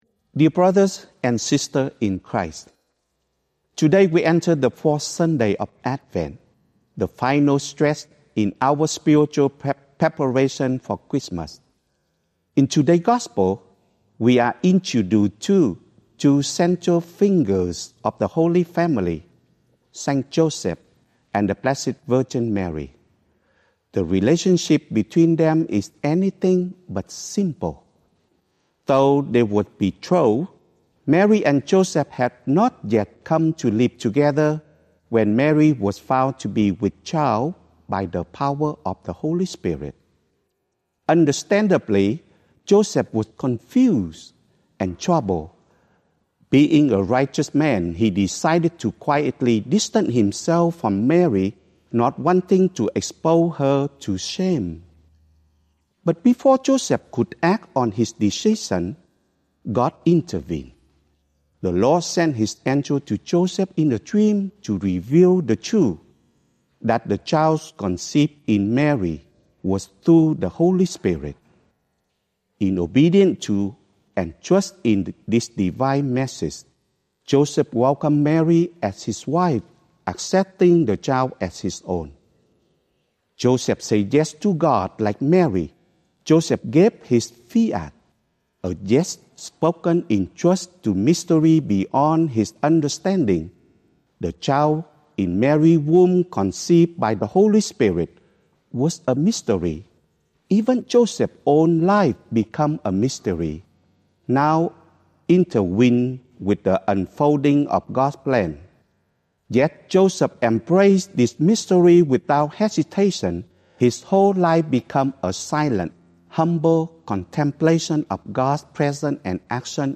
Archdiocese of Brisbane Fourth Sunday of Advent - Two-Minute Homily